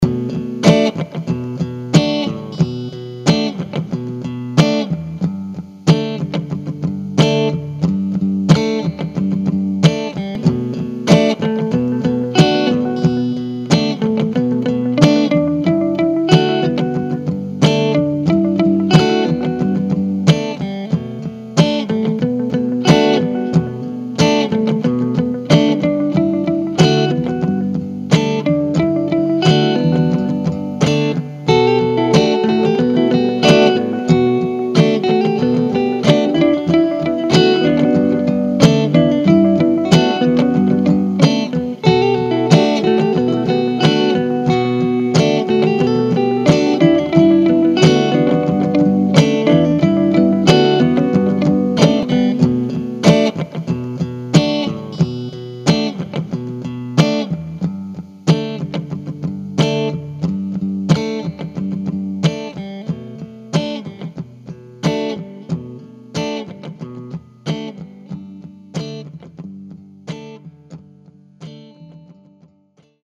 Erster Test der Loop-tauglichkeit des Creambacks Gerade bei der Gretsch G5655tg junior stelle ich fest, das der Klang mir etwas frischer erscheint.
Deshalb ist meine Beurteilung und Wahrnehmung nur auf "Zimmerlautstärke" bezogen. Kurzum,....beim Wechsel der Speaker stellte ich fest,das eine Spinne den Weg ins Gehäuse gefunden hat;... das hat mich wohl dann zu diesem Loop bewogen: Gespielt mit der Gretsch. Einziger Effekt Carbon Copy-Delay.